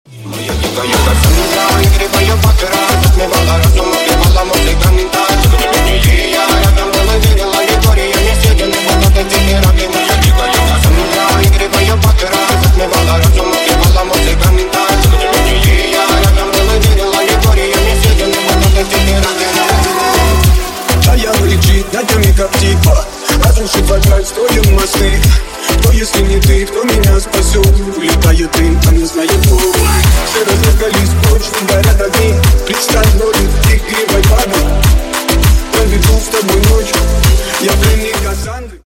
Громкие Рингтоны С Басами
Рингтоны Ремиксы » # Танцевальные Рингтоны